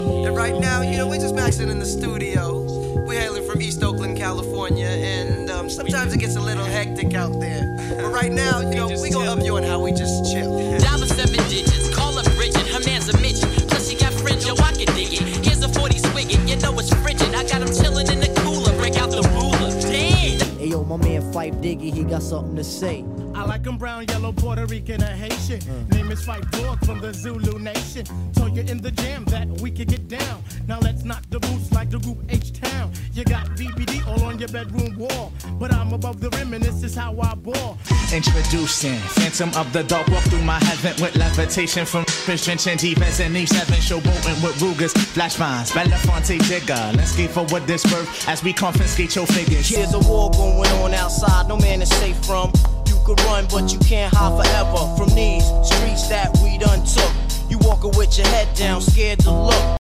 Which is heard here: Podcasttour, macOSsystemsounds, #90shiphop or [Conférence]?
#90shiphop